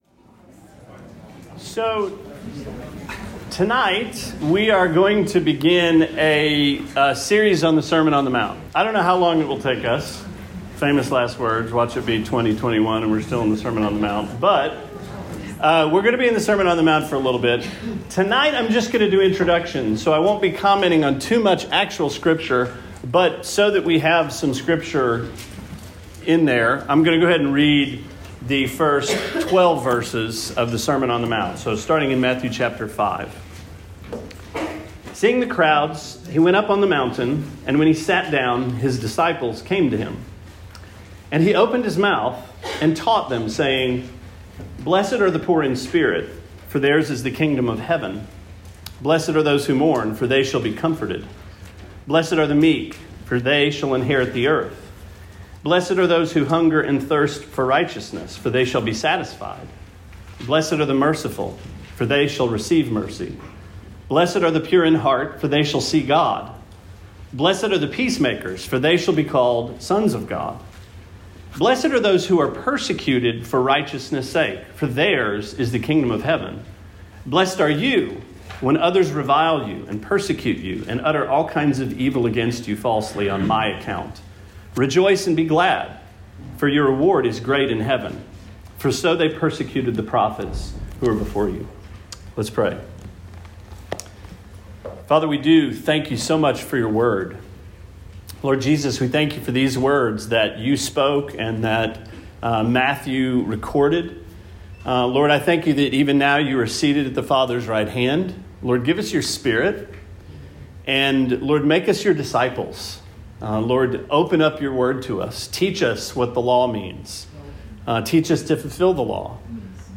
Sermon 01/10: Introduction to The Sermon on the Mount